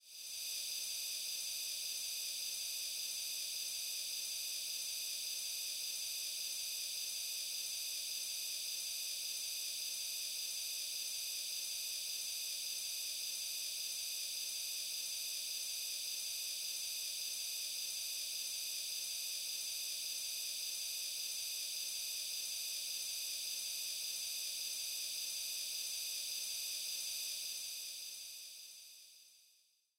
Various SFX